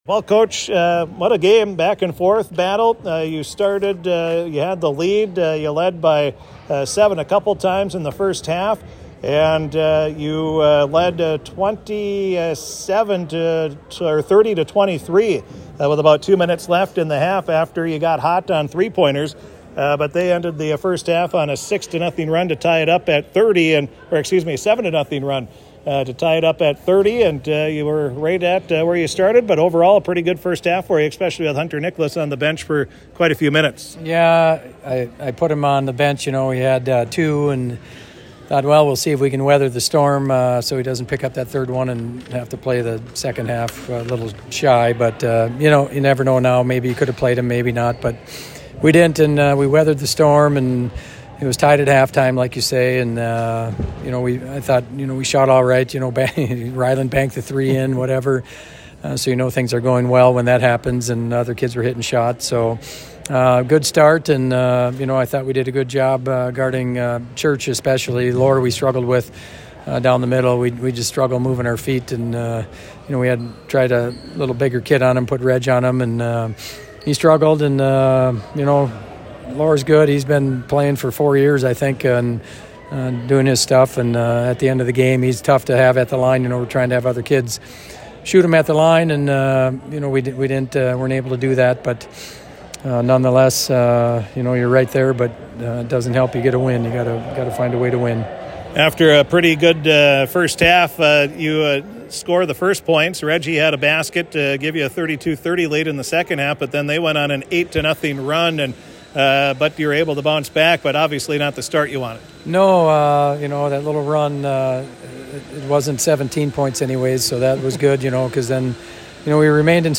post-game comments.